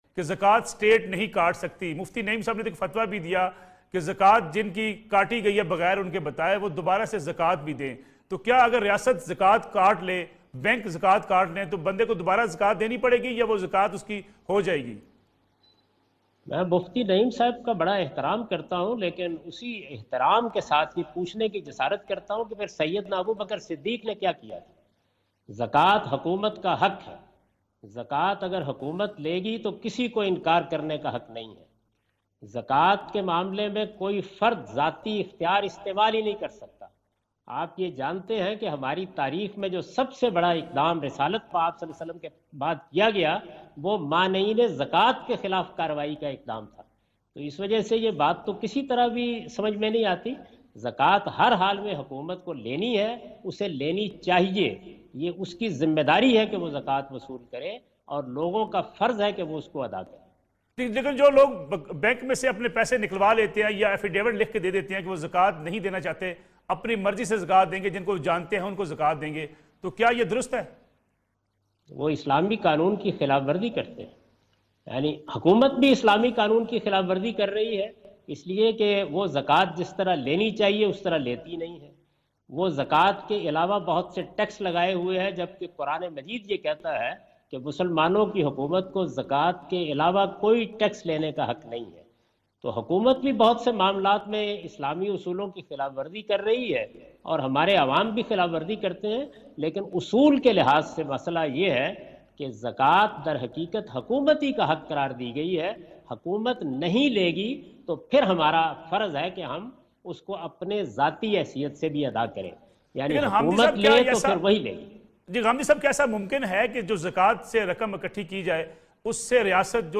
In this program Javed Ahmad Ghamidi answer the question about "Can a State take Zakah" on Neo News.